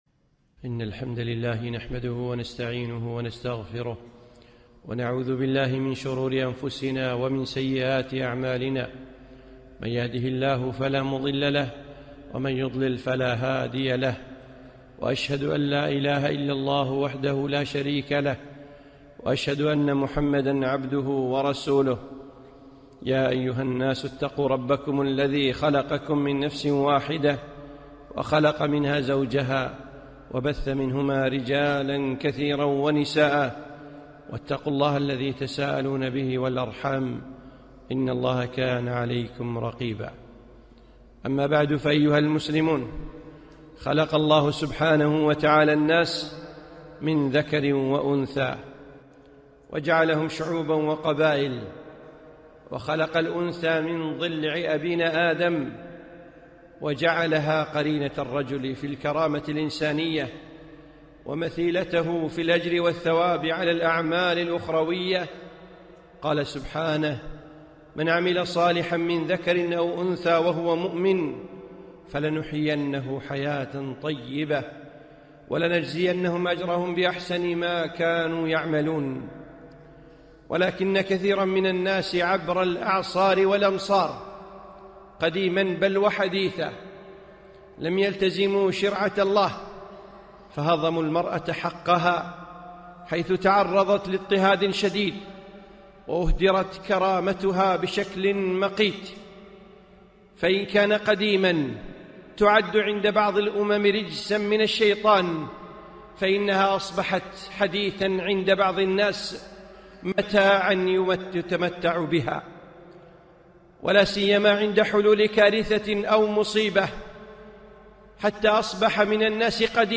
خطبة - المرأة في الإسلام